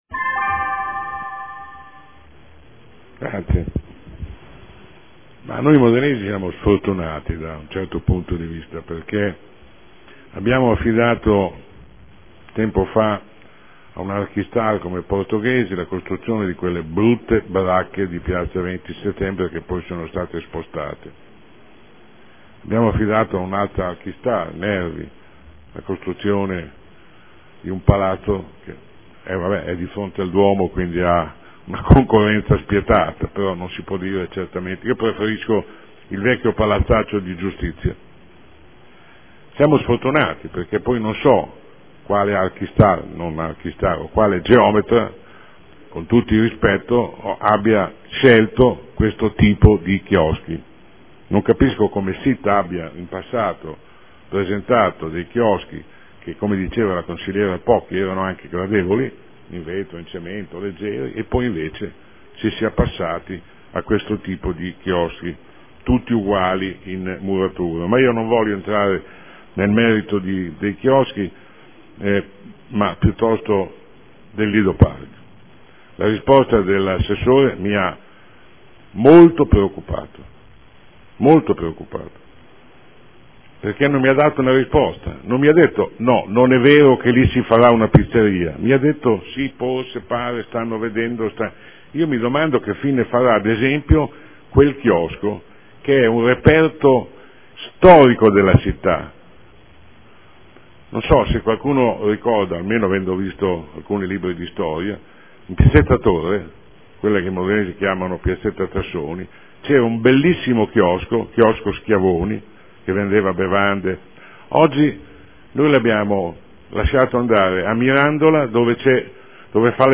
Seduta del 20/03/2014 Dibattito su interrogazioni 11 12 17